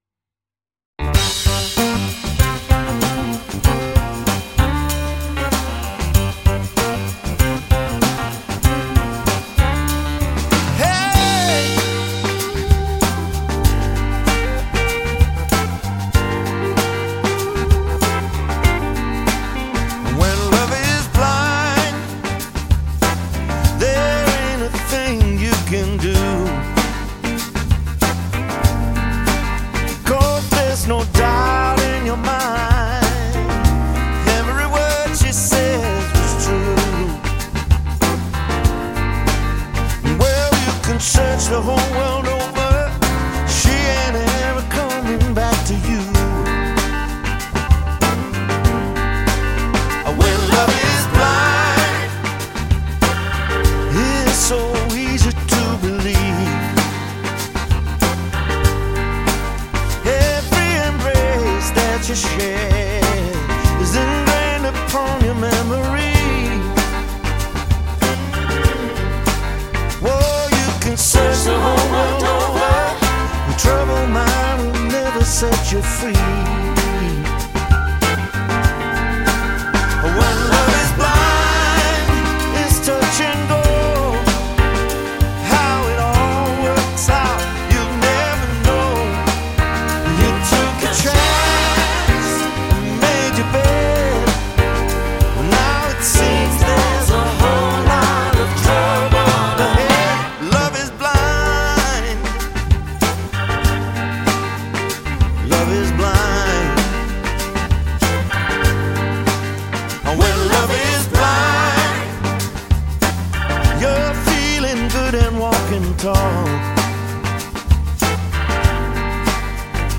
ژانر: بلوز، سول چشم‌آبی